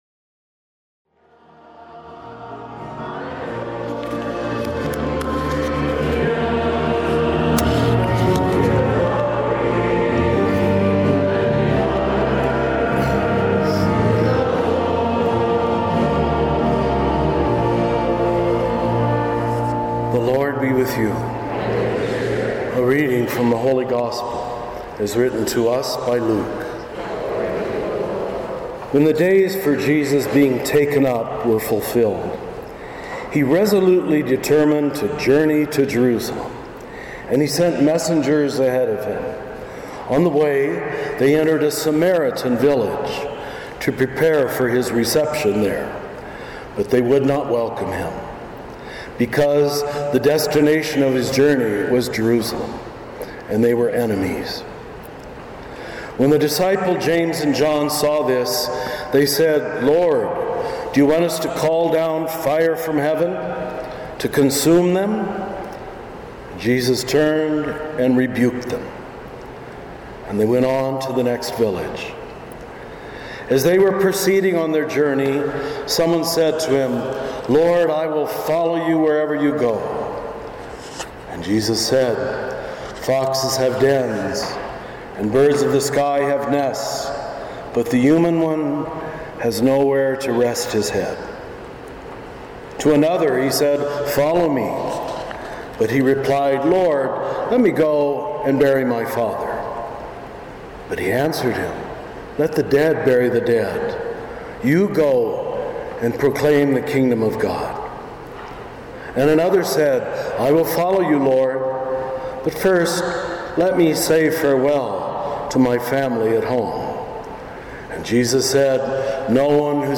Homilies with Richard Rohr